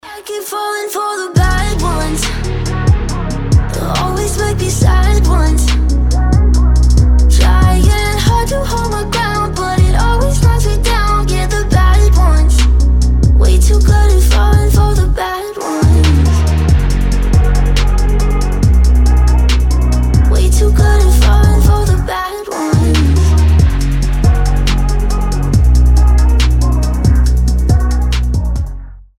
• Качество: 320, Stereo
грустные
женский голос
alternative